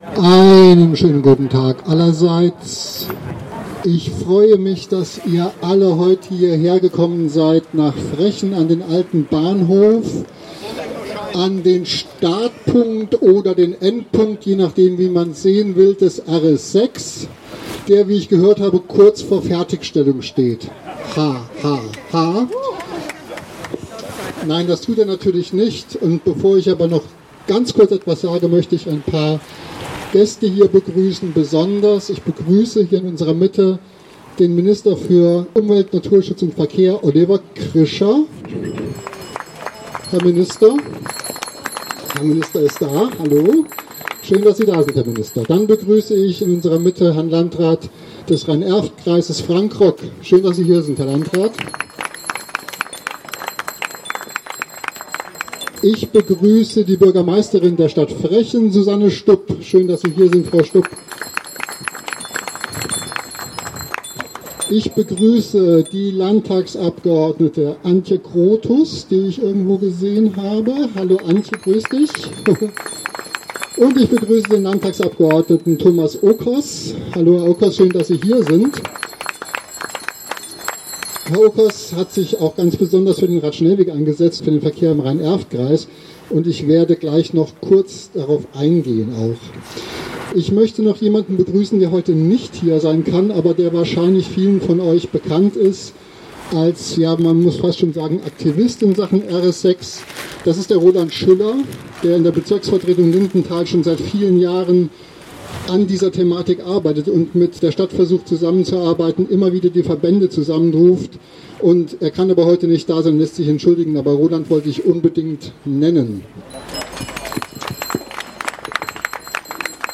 Kapitel 2: Auftaktkundgebung
Die Reden zum Thema